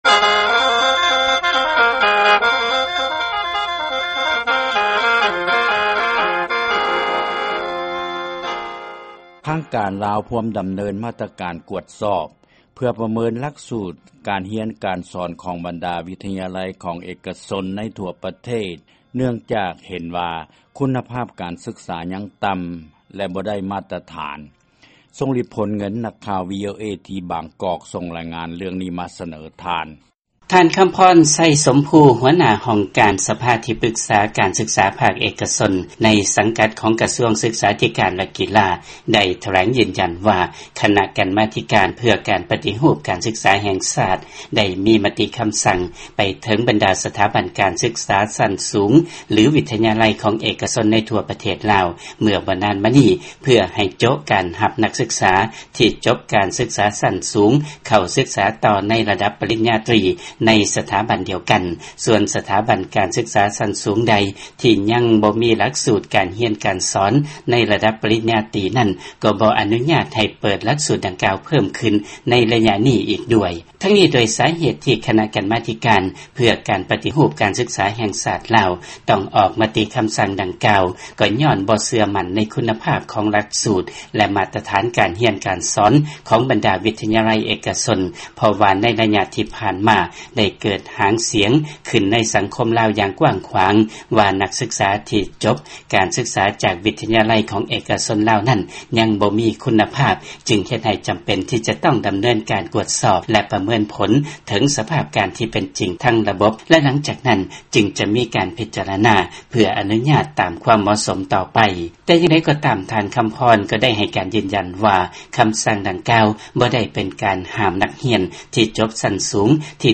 ຟັງລາຍງານຄຸນນະພາບການສຶກສາທີ່ລາວ